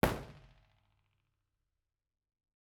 IR_EigenmikePL001R2_processed.wav